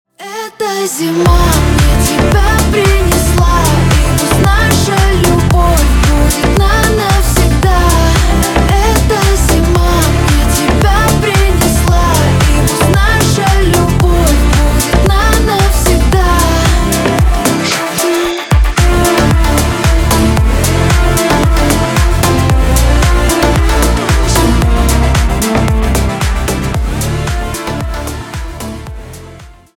• Качество: 320, Stereo
deep house